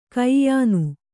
♪ kaiyānu